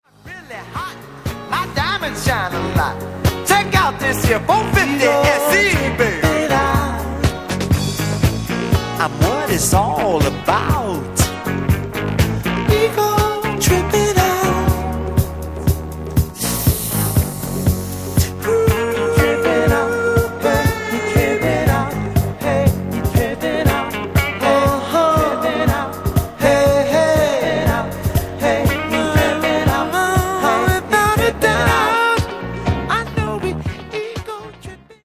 Genere:   Soul | Groove